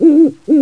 Чтобы привлечь самку, самец филина начинает петь.
filin-bubo-bubo.mp3